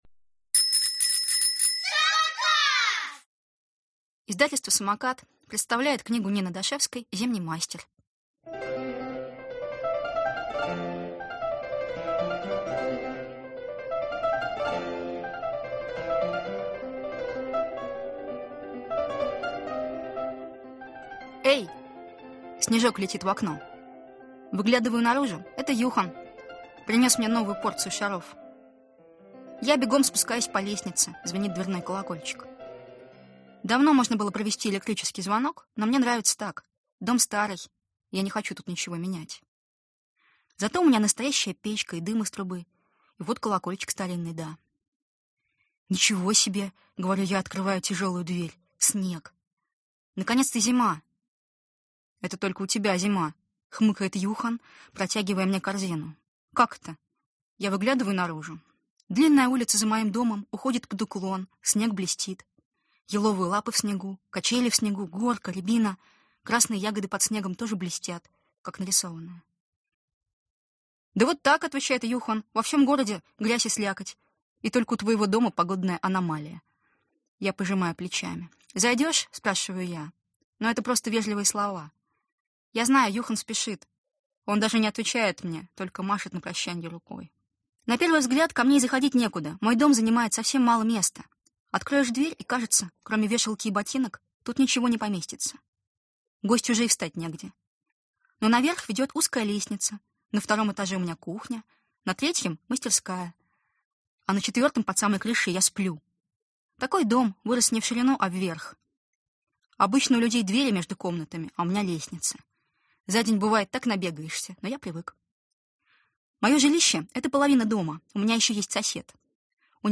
Аудиокнига Зимний мастер | Библиотека аудиокниг